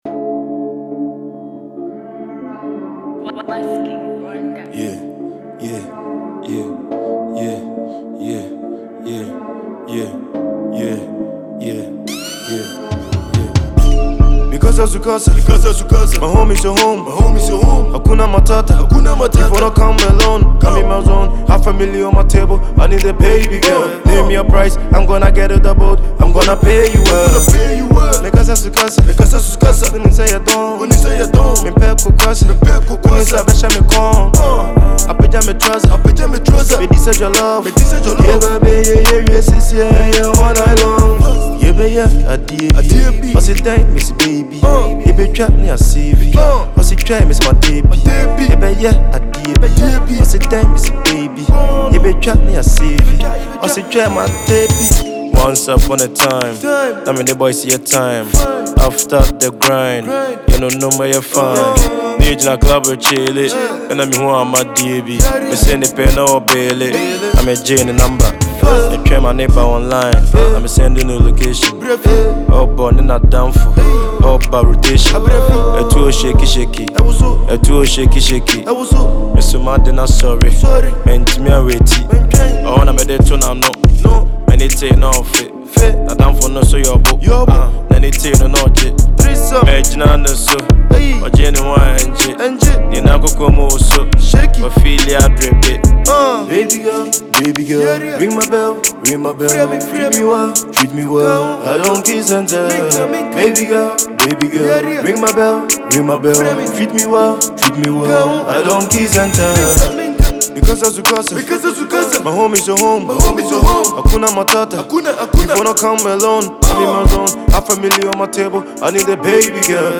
Ghanaian drill sensation
tough Asakaa anthem